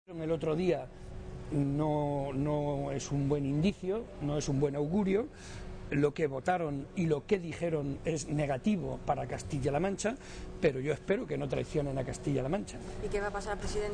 Momento de la clausura del Foro Joven de JSCM